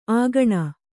♪ āgaṇa